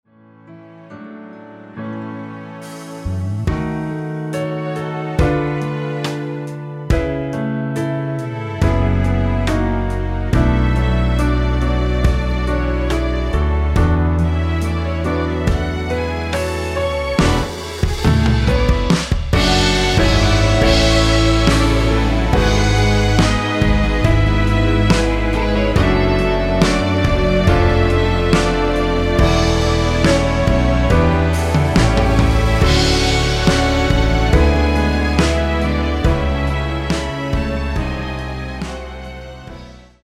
원키에서(-1)내린(1절앞 + 후렴)으로 진행되는 MR입니다.
앞부분30초, 뒷부분30초씩 편집해서 올려 드리고 있습니다.